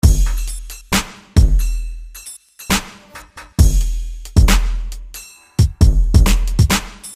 陷阱鼓循环1
描述：只是一个非常简单的循环，用808踢球、小鼓、开帽和踢球做的！
Tag: 128 bpm Trap Loops Drum Loops 1.26 MB wav Key : Unknown